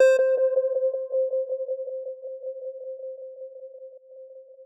Pluck - Samus.wav